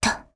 Xerah-Vox_Landing_jp.wav